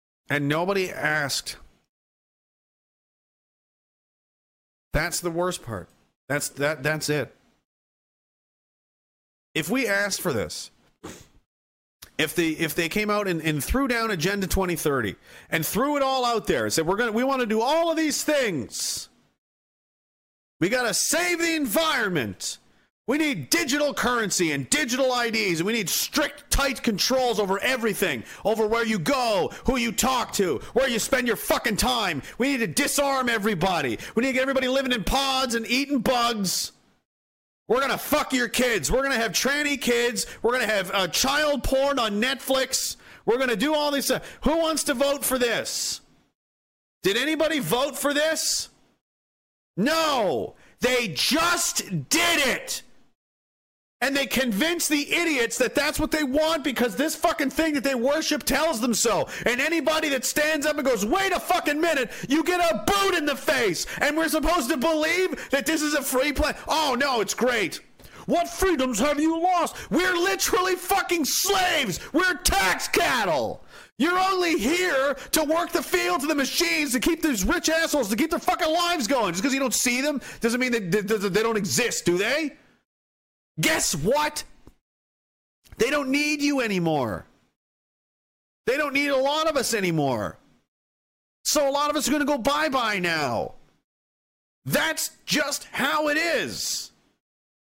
epic rant